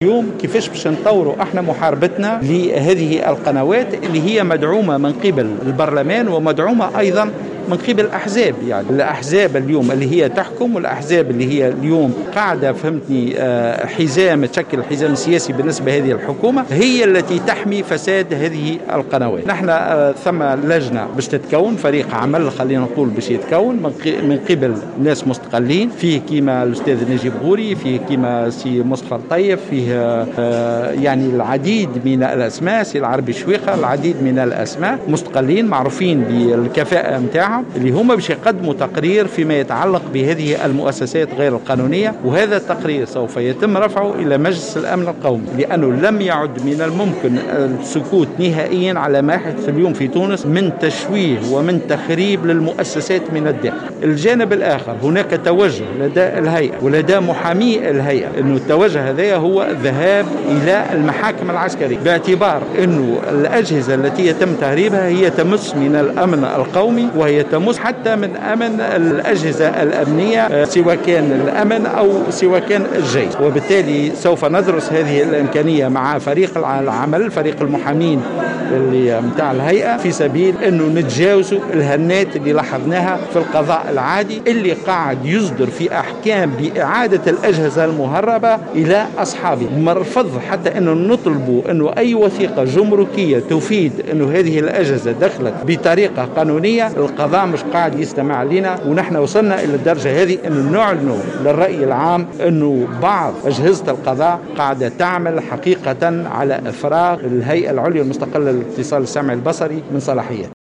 قال عضو الهيئة العليا المستقلة للاتصال السمعي و البصري هشام السنوسي في تصريح لموفدة "الجوهرة أف أم" على هامش ندوة صحفية اليوم الثلاثاء بالعاصمة، إن هناك وضعا خطيرا في الدولة يتمثل في توظيف مؤسسات الدولة لخدمة أجندات حزبية.